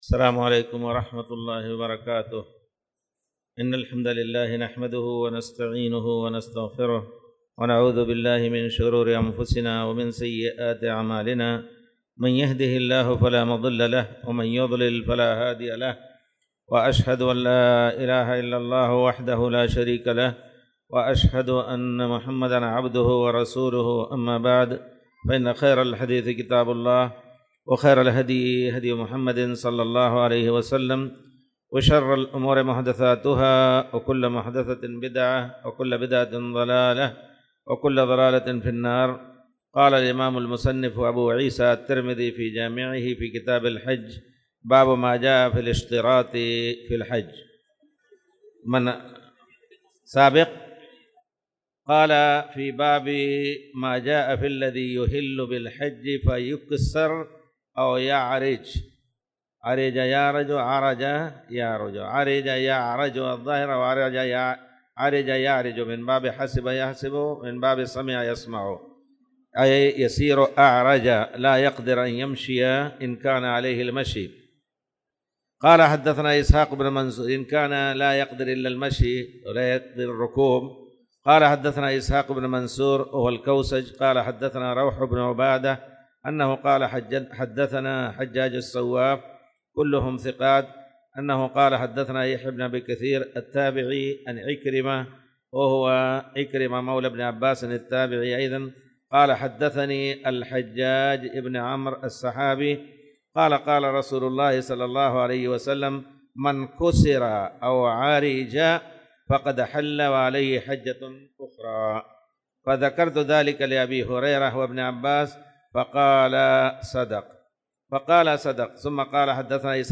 تاريخ النشر ١٣ شعبان ١٤٣٧ المكان: المسجد الحرام الشيخ